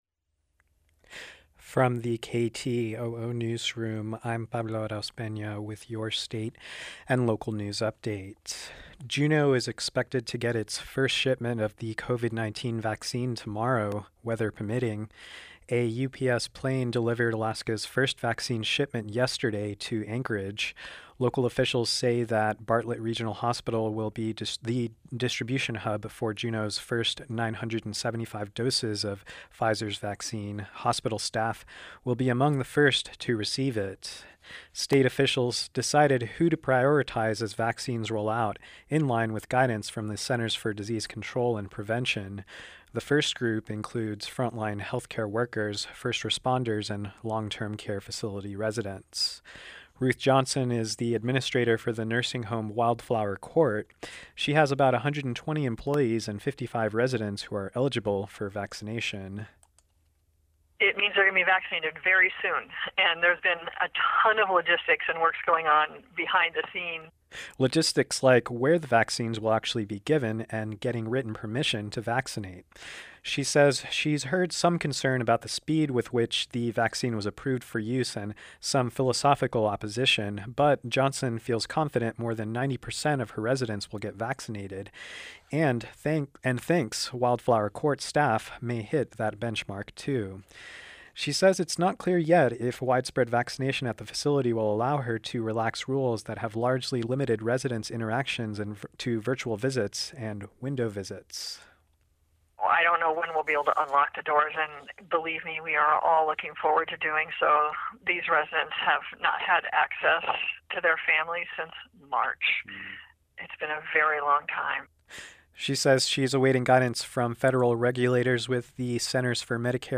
Newscast – Monday, Dec. 14, 2020